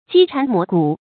積讒磨骨 注音： ㄐㄧ ㄔㄢˊ ㄇㄛˊ ㄍㄨˇ 讀音讀法： 意思解釋： 指不斷的毀謗能使人毀滅。